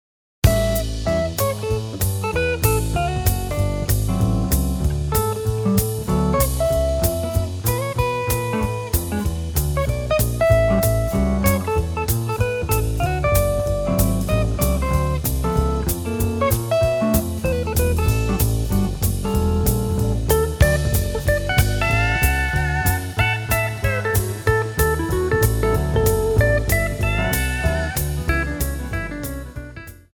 seven-string acoustic guitar